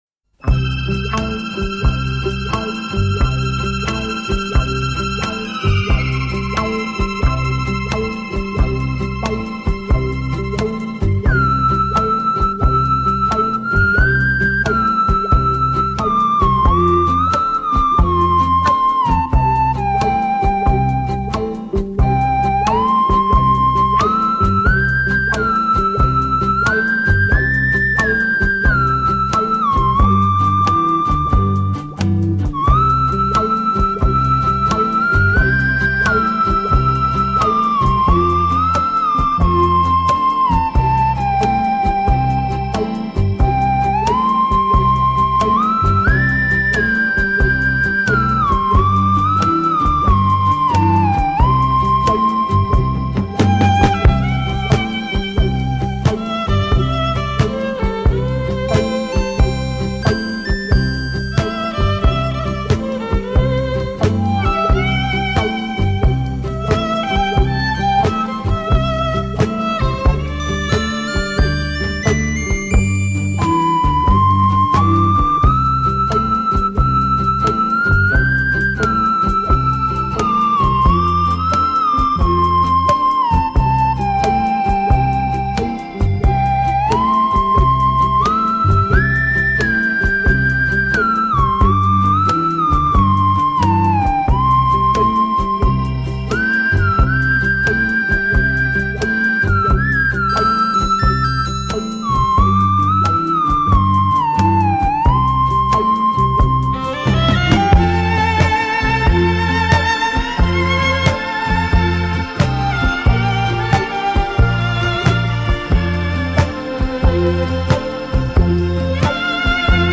柔美恒久的音乐，带给您昔日的美好回忆。